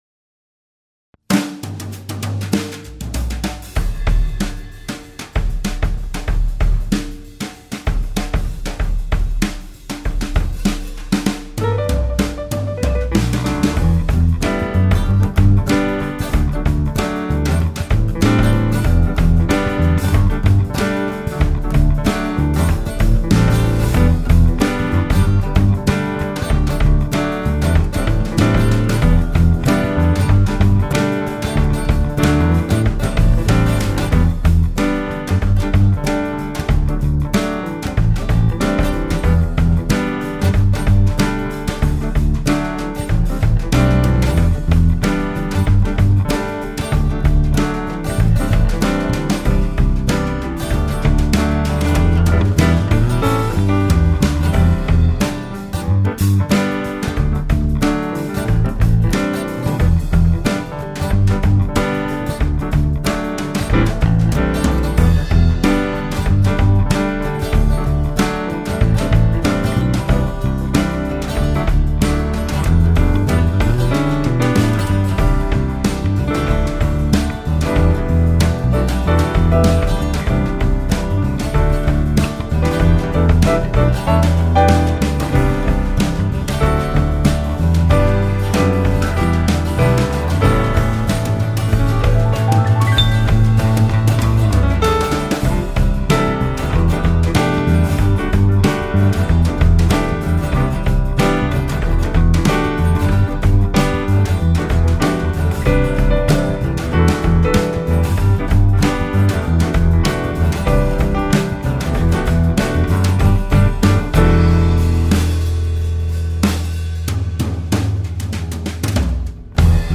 . die etwas andere Coverband ...